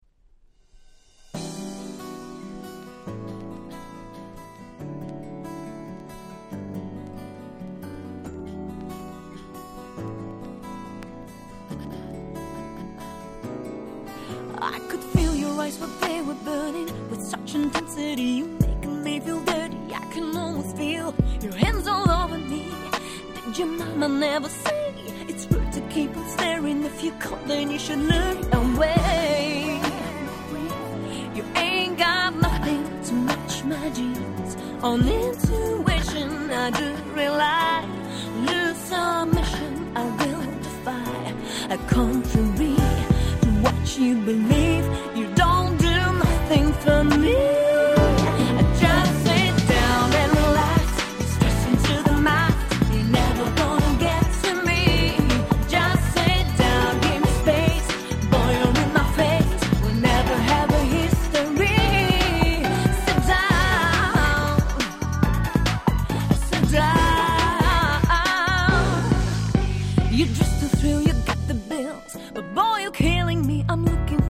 02' Nice Cover R&B !!